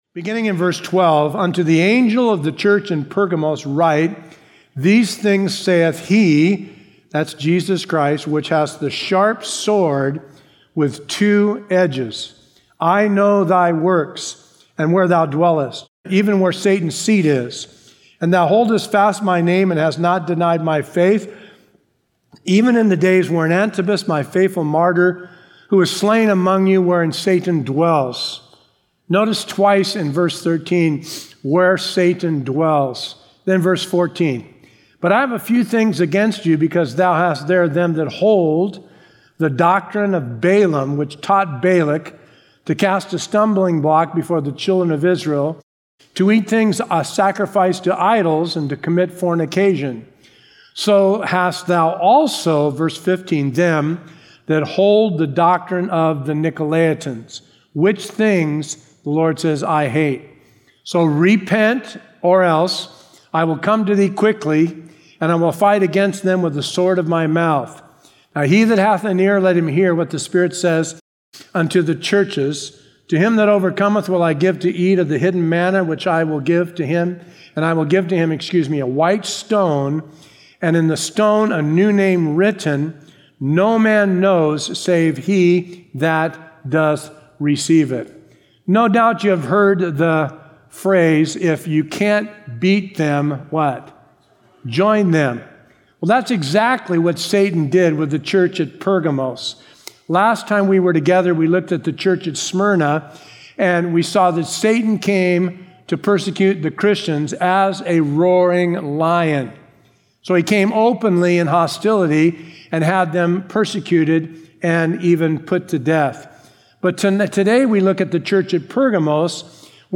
A verse-by-verse expository sermon through Revelation 2:12-17